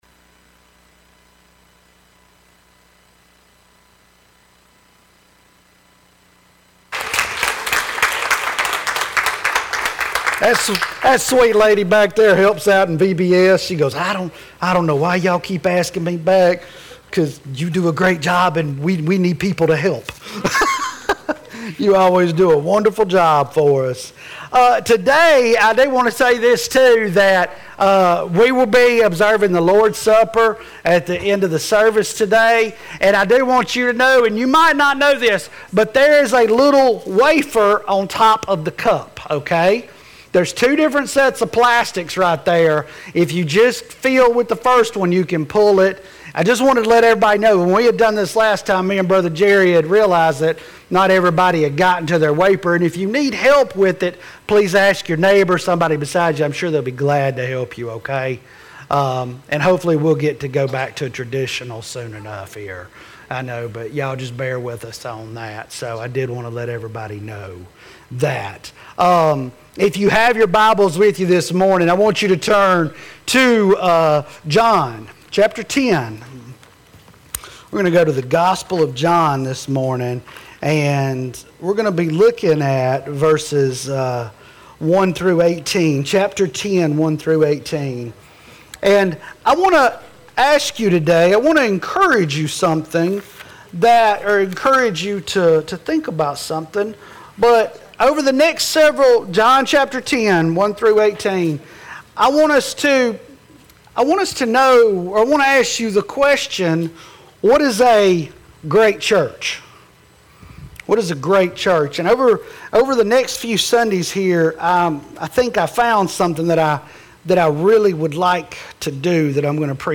Autaugaville Baptist Church Sermons